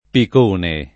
[ pik 1 ne ]